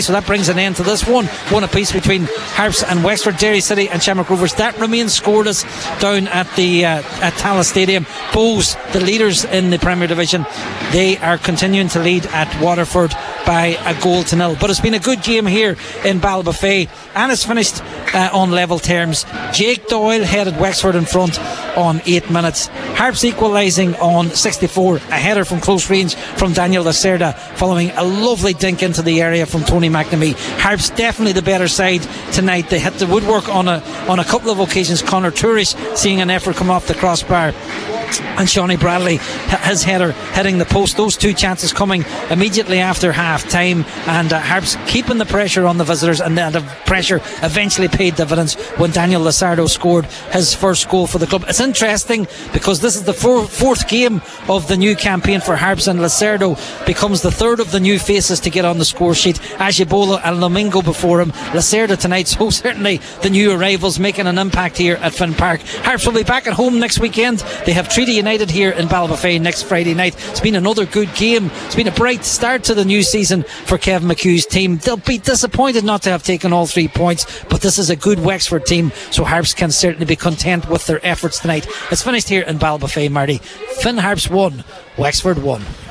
live at full time for Highland Radio Sport…